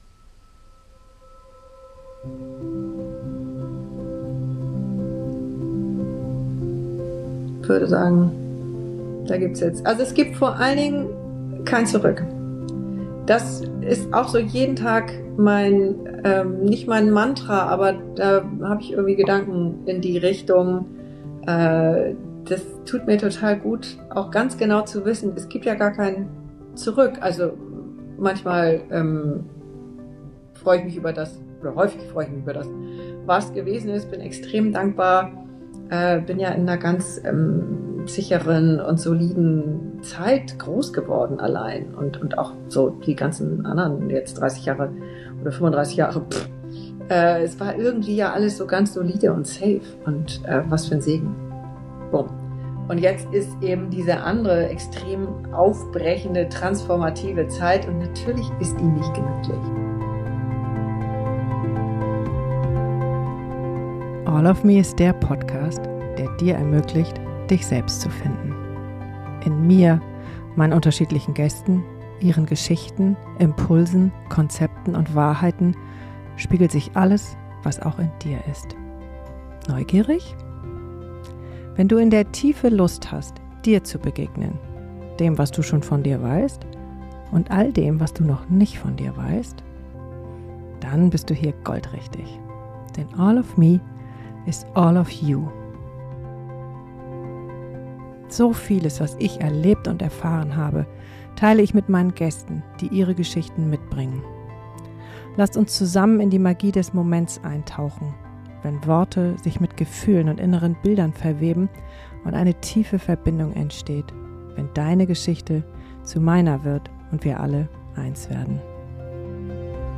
Beschreibung vor 6 Tagen Kein Zurück: Vorwärts durch Veränderung Endlich mal wieder eine Solo-Folge allofme.